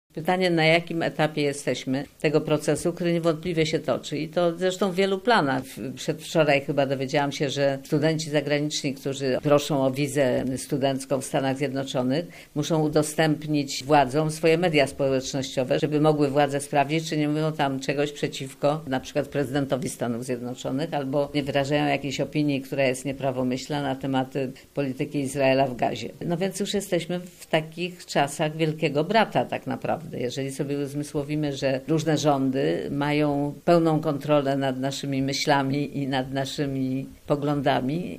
Agnieszka Holland w rozmowie z Radiem Poznań: "Szczepionka Holocaustu przestaje działać"
- mówi artystka.